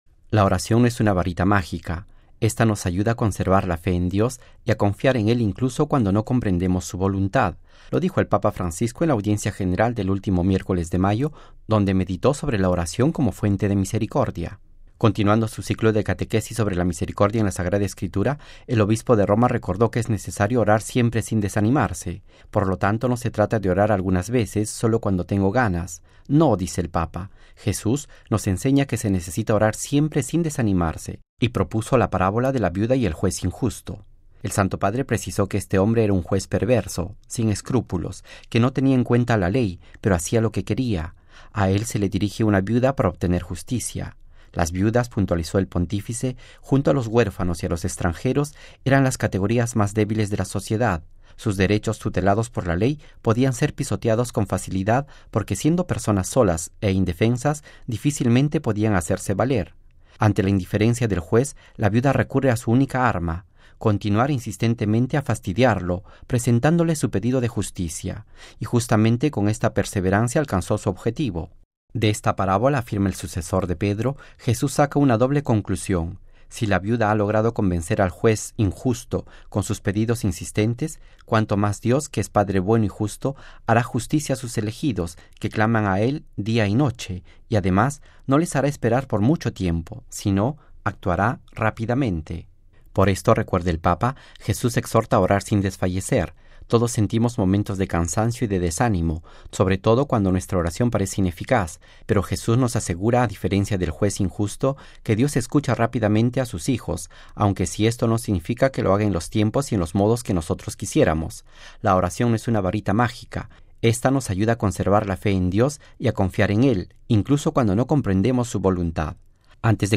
(RV).- “¡La oración no es una varita mágica! Ésta nos ayuda a conservar la fe en Dios y a confiar en Él incluso cuando no comprendemos su voluntad”, lo dijo el Papa Francisco en la Audiencia General del último miércoles de mayo, donde meditó sobre la oración como fuente de misericordia.